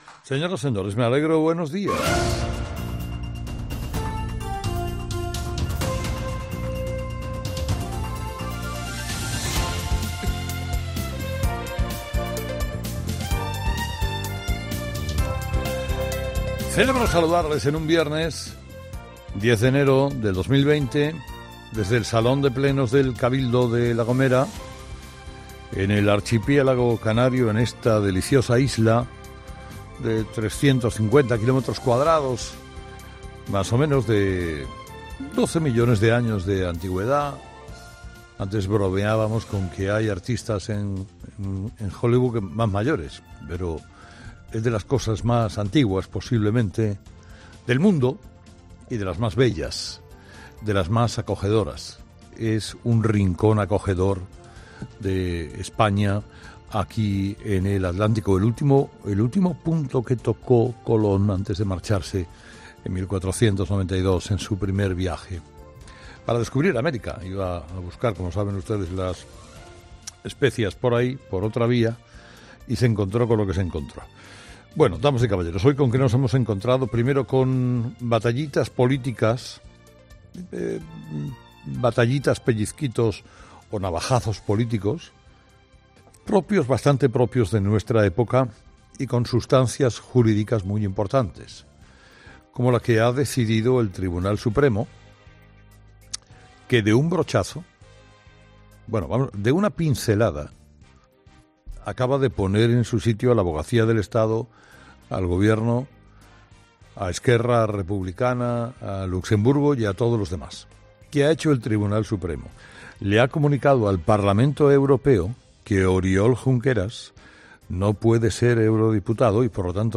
ESCUCHA AQUÍ EL MONÓLOGO DE HERRERA ¿Qué ha hecho el Tribunal Supremo?